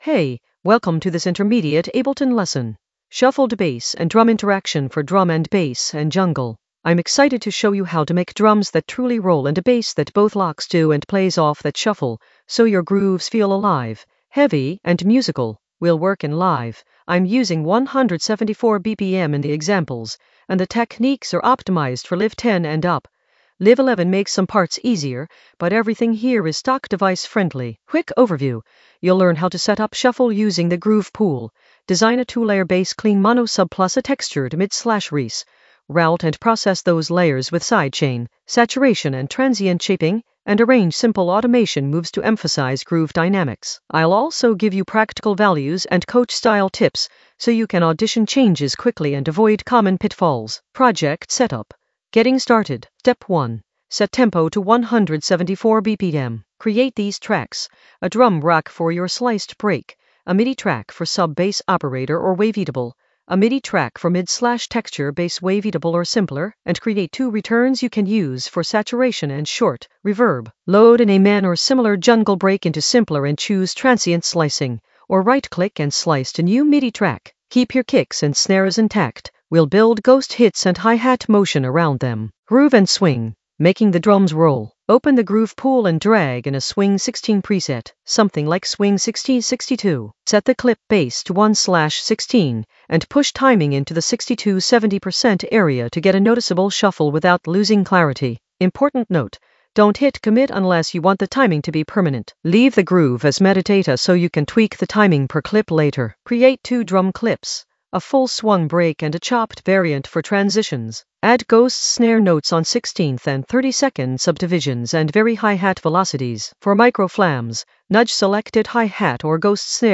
An AI-generated intermediate Ableton lesson focused on Shuffled bass and drum interaction in the Groove area of drum and bass production.
Narrated lesson audio
The voice track includes the tutorial plus extra teacher commentary.